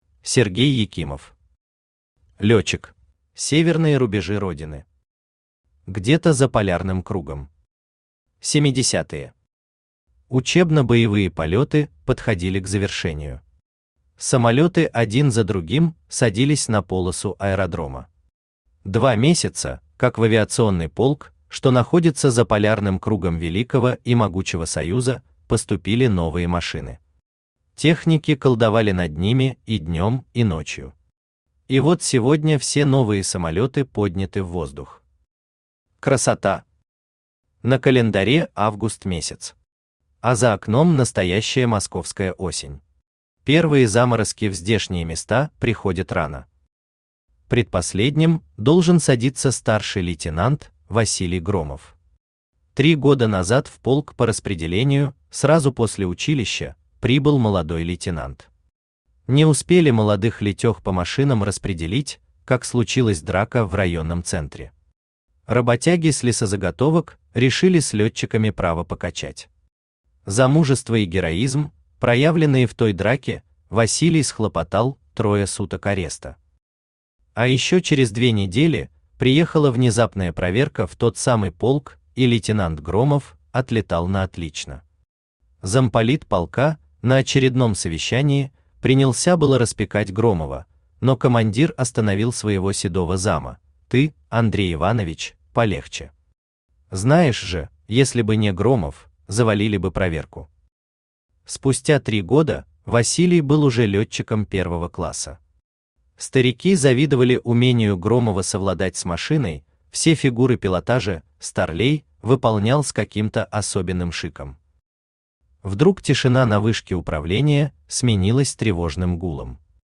Аудиокнига Лётчик | Библиотека аудиокниг